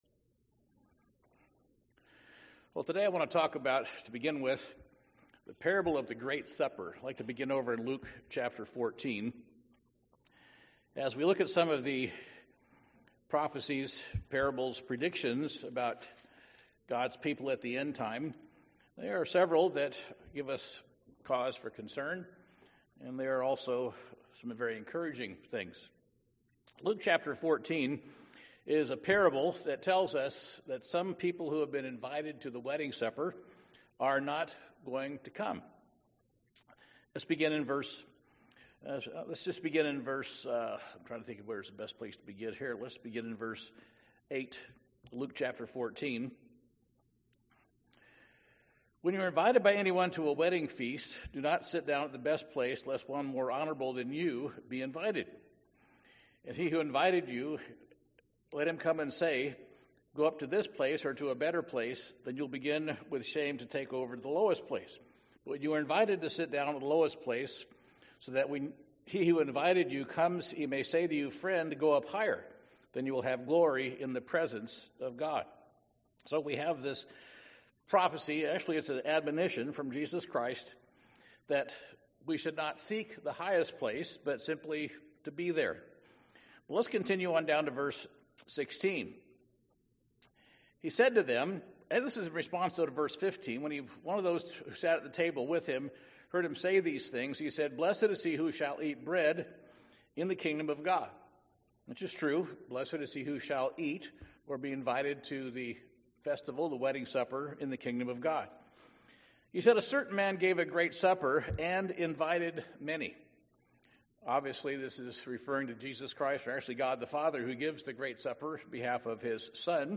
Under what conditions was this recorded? Given in Portland, OR